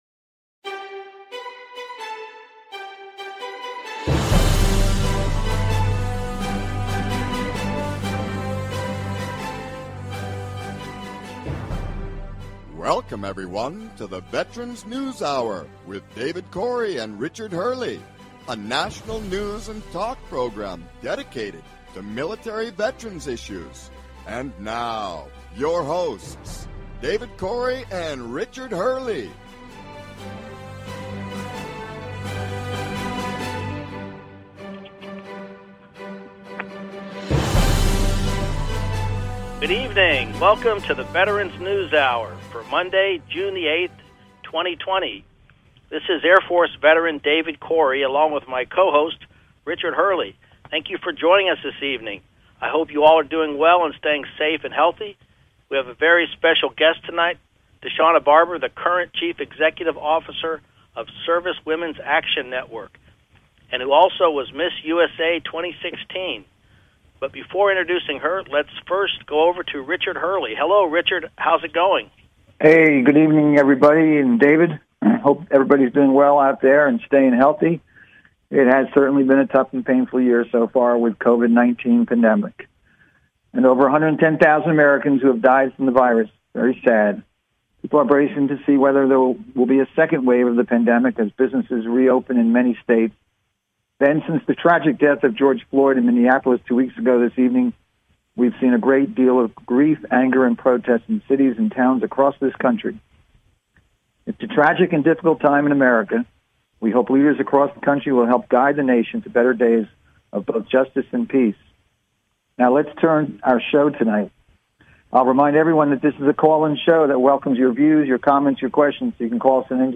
Talk Show Episode
Guest, Deshauna Barber, Service Women’s Action Network and Miss USA 2016